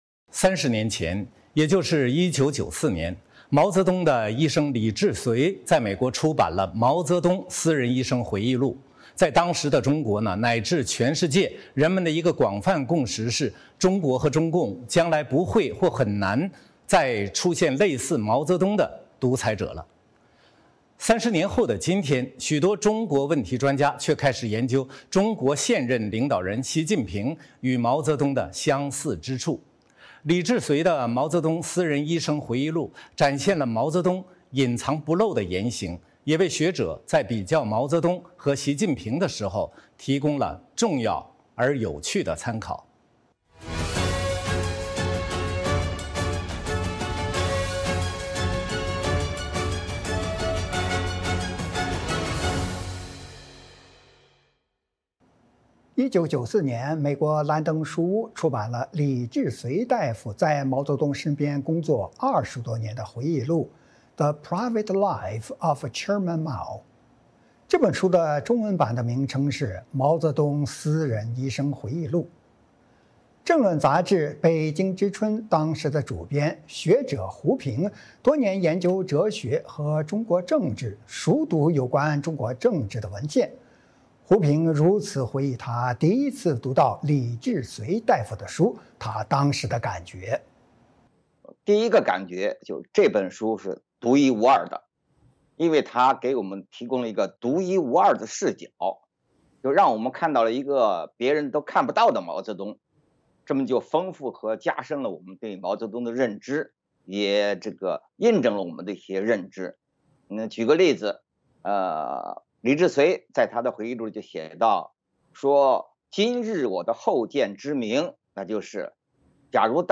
《纵深视角》节目进行一系列人物专访，受访者所发表的评论不代表美国之音的立场。